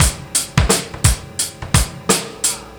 Index of /90_sSampleCDs/Best Service ProSamples vol.24 - Breakbeat [AKAI] 1CD/Partition A/TRIBEVIBE086